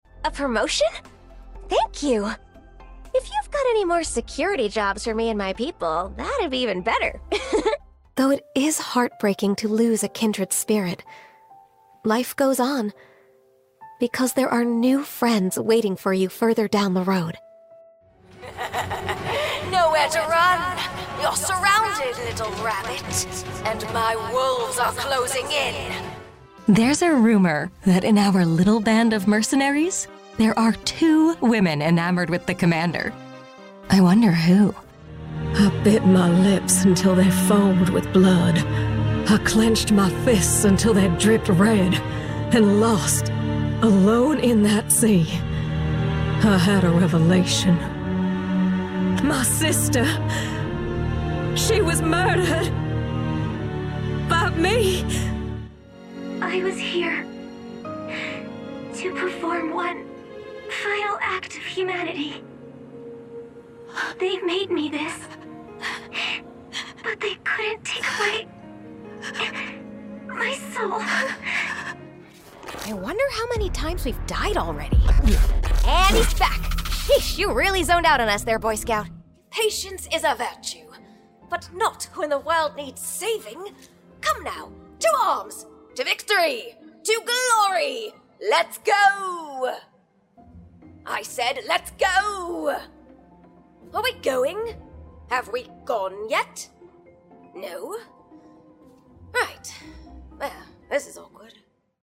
Featuring clips from Arknights, Genshin Impact, Saints Row, Fire Emblem Heroes, Shadowverse, Scarlet Nexus, Blackout Protocol, and Tiny Tina's Wonderlands.